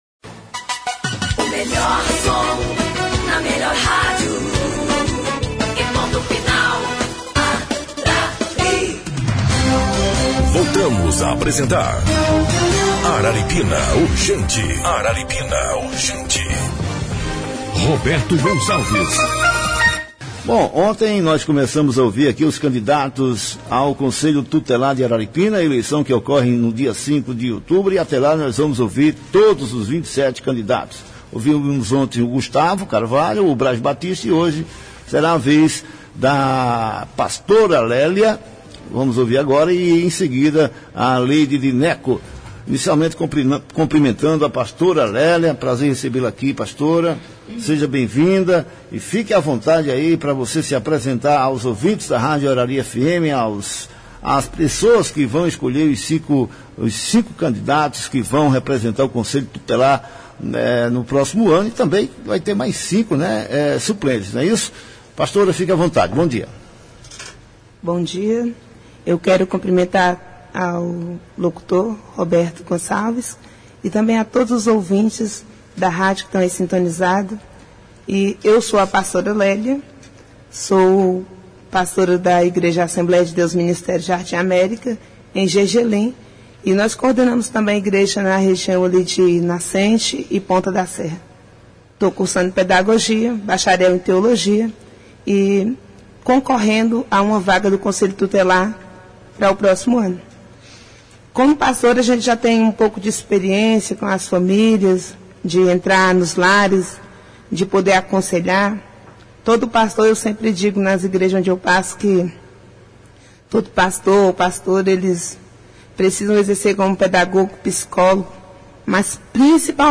O programa Araripina Urgente começa às 7h e as entrevistas vão ao ar a partir da 7h30.